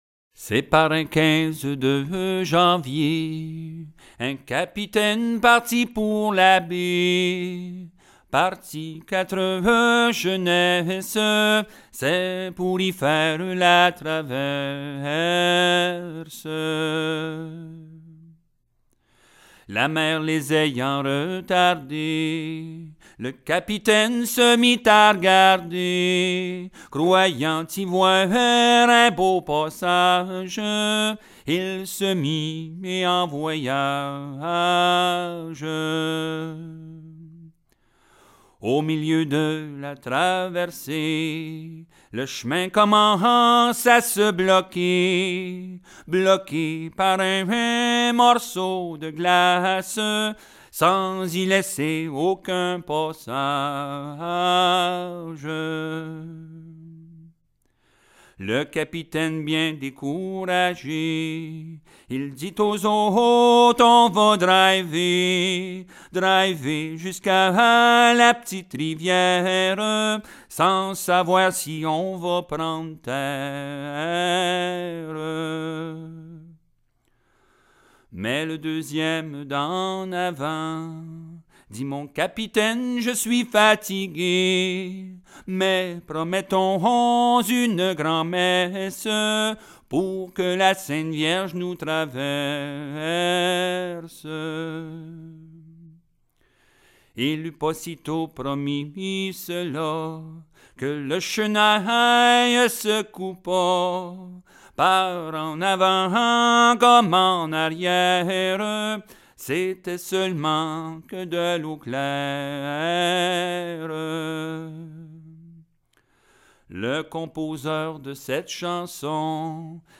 à l'île-aux-Coudres, dans le Charlevoix par le cinéaste Pierre Perreault
Genre strophique